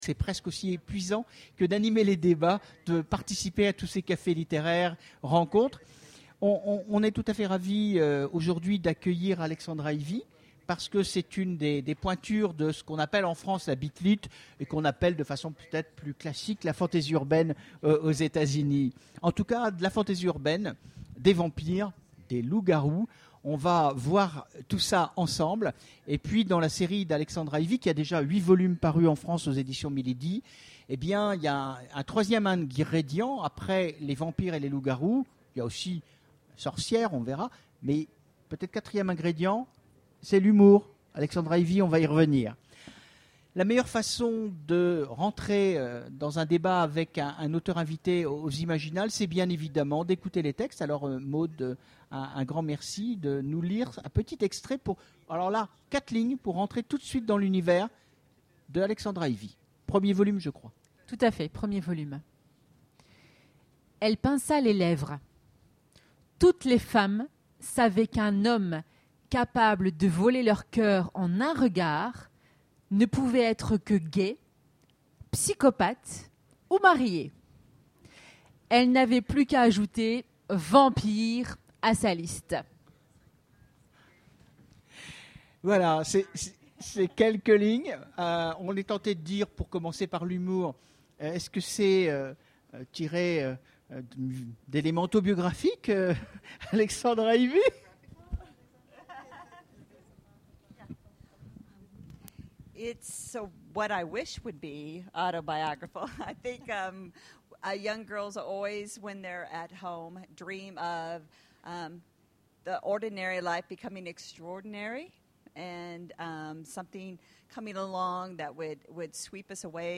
Imaginales 2013 : Conférence Entretien avec... Alexandra Ivy
- le 31/10/2017 Partager Commenter Imaginales 2013 : Conférence Entretien avec... Alexandra Ivy Télécharger le MP3 à lire aussi Alexandra Ivy Genres / Mots-clés Rencontre avec un auteur Conférence Partager cet article